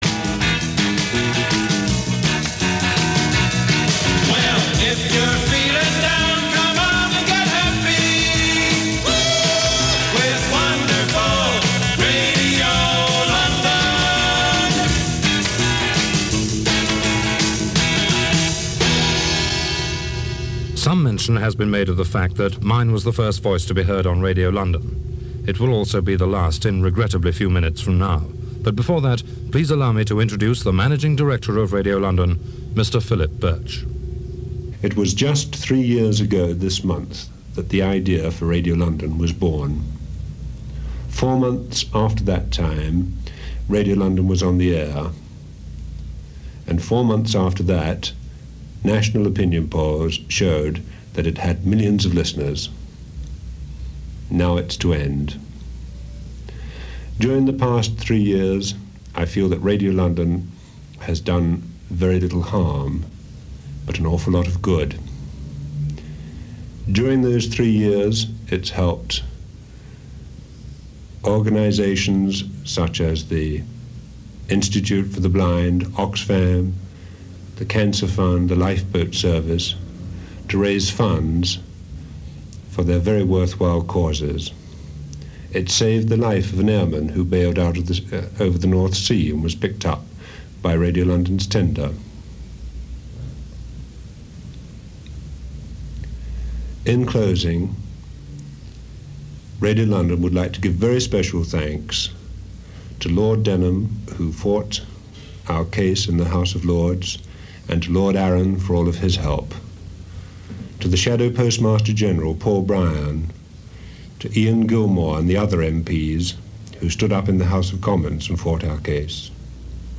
Radio 355 closing